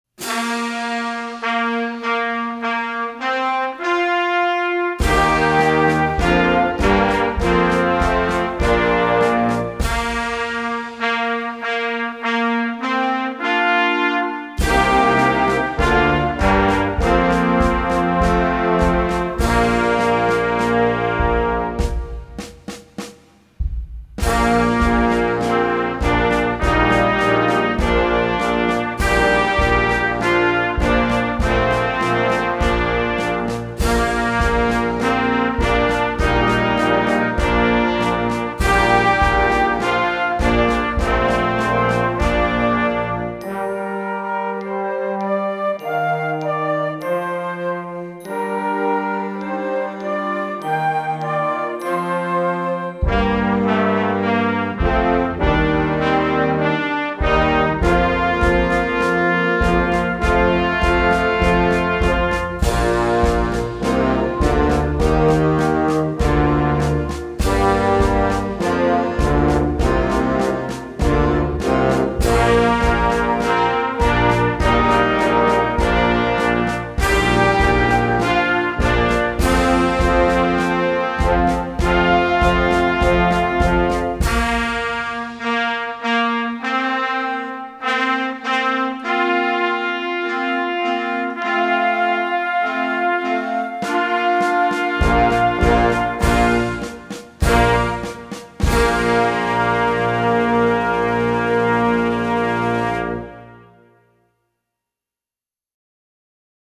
Blasmusik für Jugendkapelle Schwierigkeit
Blasorchester